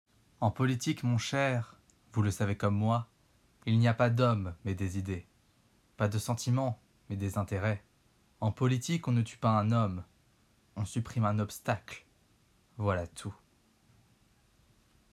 Extrait du roman Dumas Le comte de Monte-Cristo
16 - 26 ans